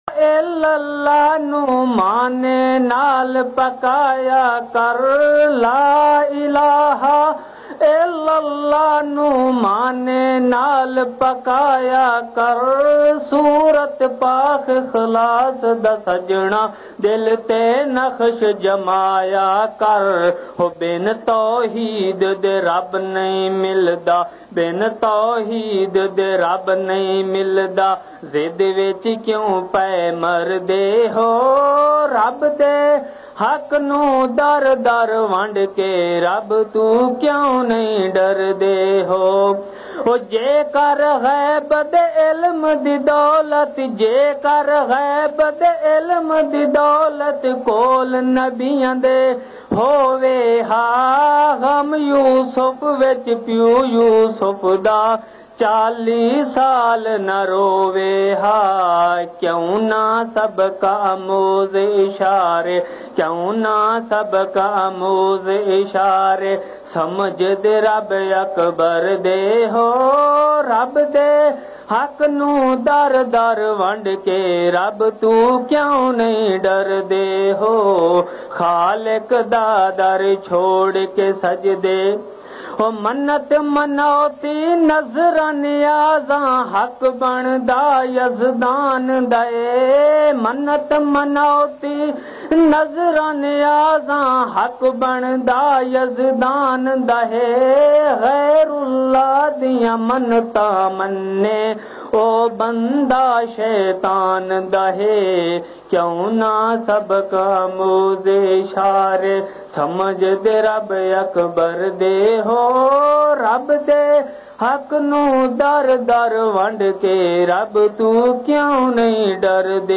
Taqwa Tawakkal bayan